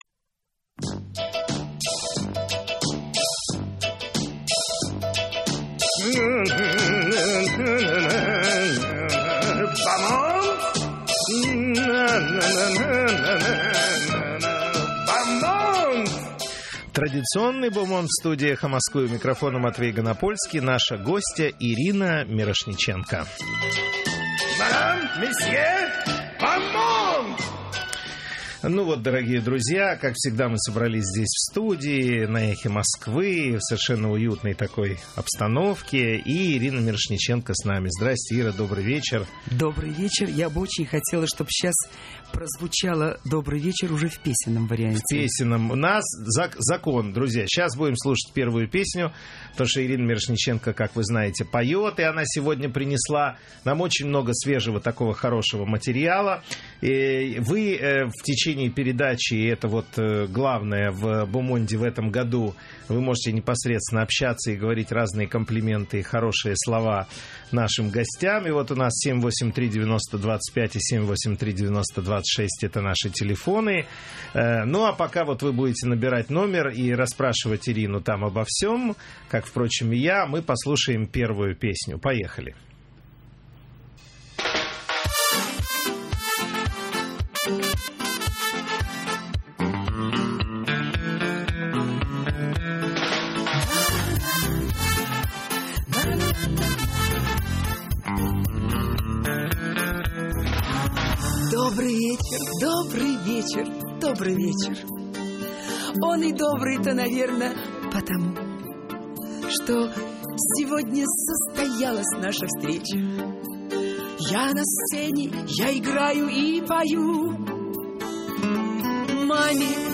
В эфире радиостанции «Эхо Москвы» актриса Ирина Мирошниченко.
Эфир ведет Матвей Ганапольский.